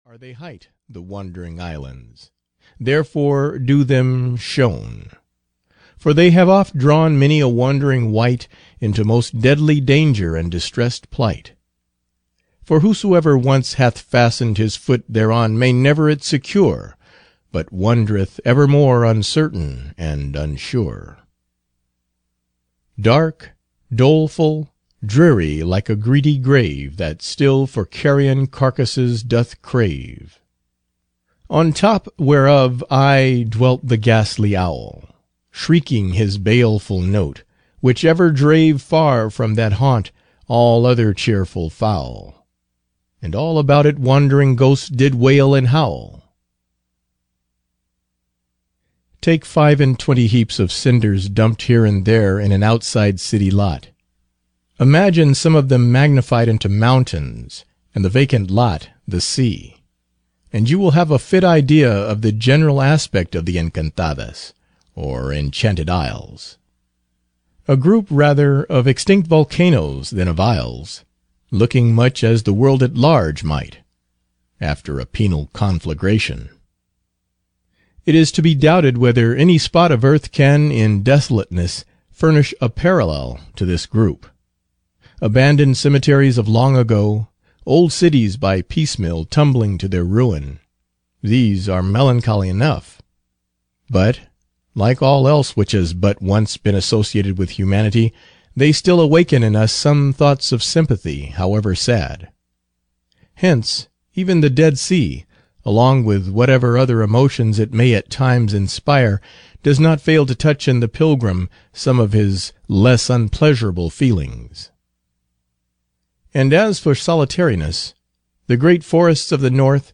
The Encantadas (EN) audiokniha
Ukázka z knihy